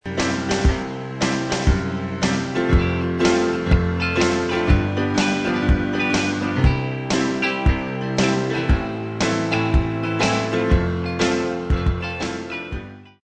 The theme is that of some simple blues sounds interspersed between a bizarre chord progression.
Then there's a super basic drum track to fill things out.